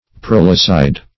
Prolicide \Prol"i*cide\, n. [L. proles offspring + caedere to